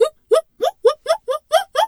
pgs/Assets/Audio/Animal_Impersonations/zebra_whinny_03.wav at master
zebra_whinny_03.wav